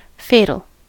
fatal: Wikimedia Commons US English Pronunciations
En-us-fatal.WAV